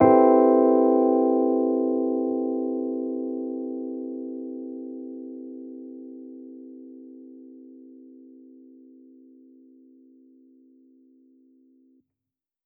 Index of /musicradar/jazz-keys-samples/Chord Hits/Electric Piano 2
JK_ElPiano2_Chord-Cmaj13.wav